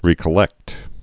(rēkə-lĕkt)